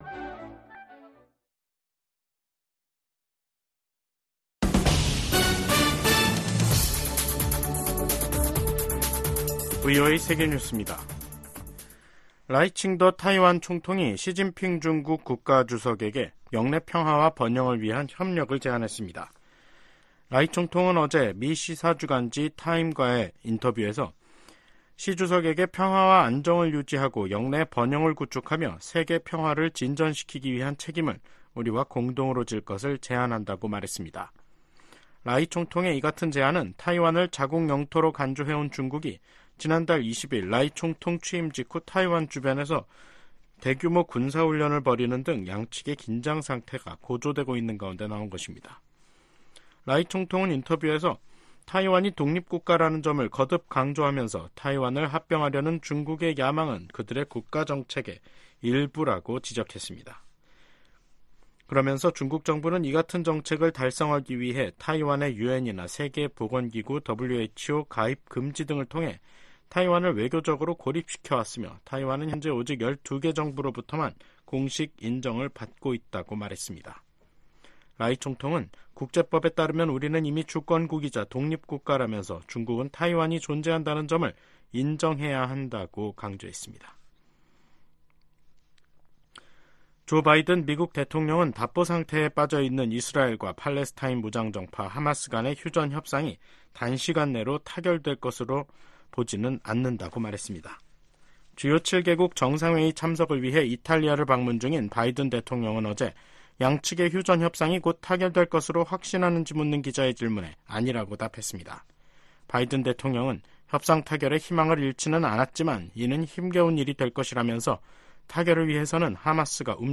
VOA 한국어 간판 뉴스 프로그램 '뉴스 투데이', 2024년 6월 14일 3부 방송입니다. 블라디미르 푸틴 러시아 대통령의 방북 임박설 속에 김일성 광장에 ‘무대’ 추정 대형 구조물이 등장했습니다. 미국의 전문가들은 푸틴 러시아 대통령의 방북이 동북아시아의 안보 지형을 바꿀 수도 있다고 진단했습니다. 미국 정부가 시행하는 대북 제재의 근거가 되는 ‘국가비상사태’가 또다시 1년 연장됐습니다.